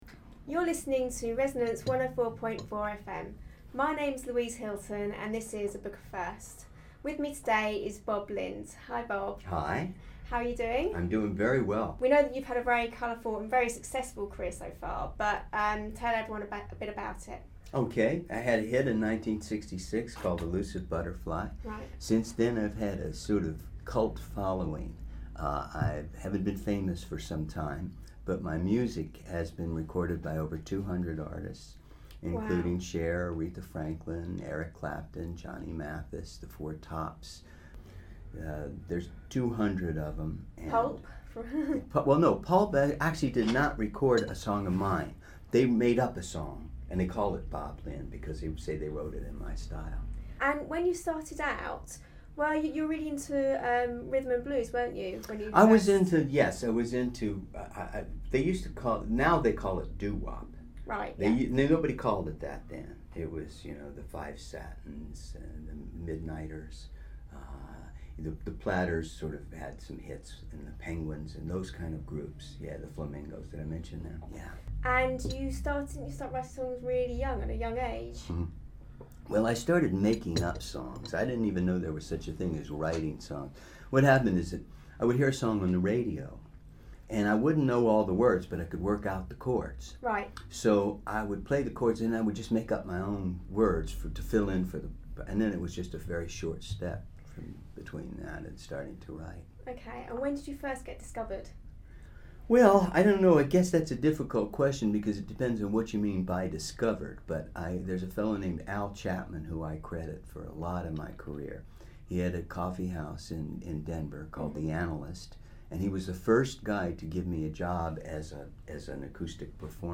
This is Bob Lind's first time having an interview archived on his own website !